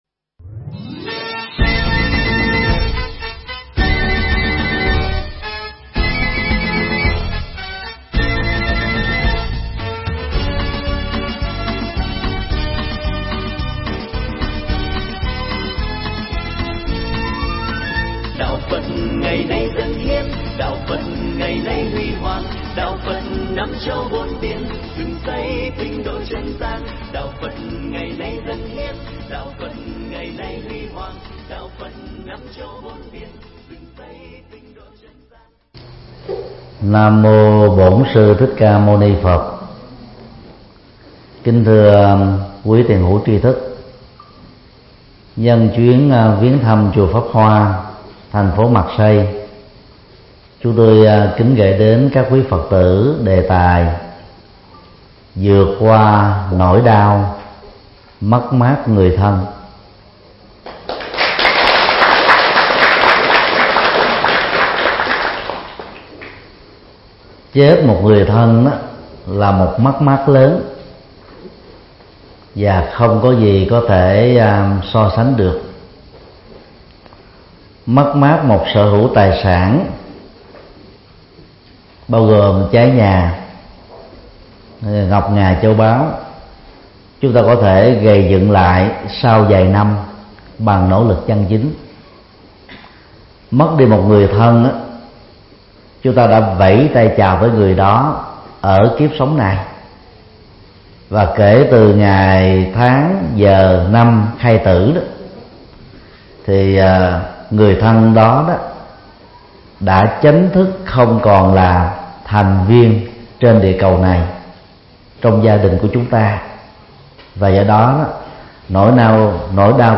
Mp3 Thuyết Giảng Vượt qua nỗi đau mất mát người thân
Giảng tại chùa Pháp Hoa,TP. Marseille, Pháp